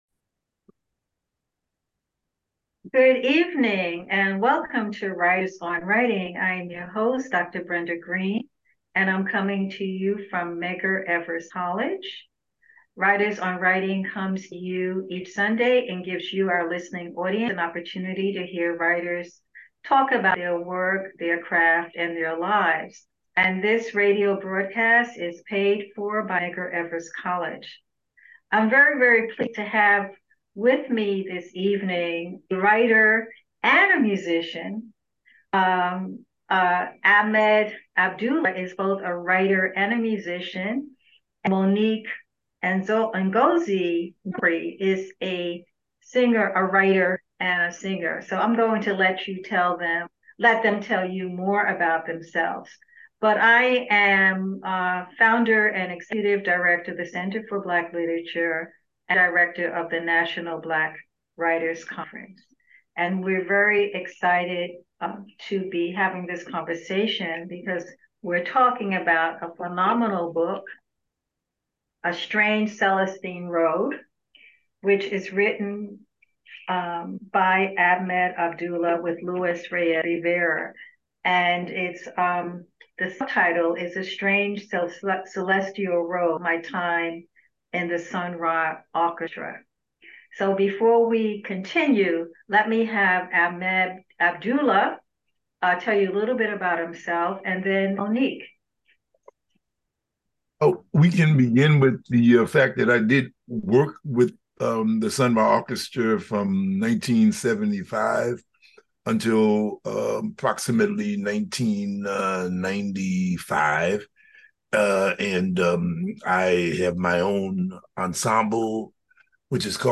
Writers on Writing Interview